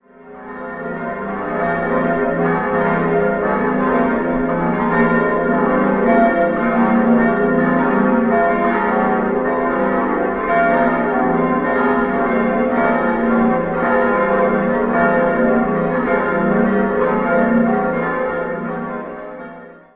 6-stimmiges Geläute: f'-g'-as'-a'-b'-c'' Glocke 1: Petit&Edelbrock 2005, Glocke 2: Duistervalt 1416, Glocke 3: D. und H. von Cölln 1571, Glocke 4: Duistervalt 1393, Glocke 5: unbekannt 14. Jahrhundert, Glocke 6: Mark 2000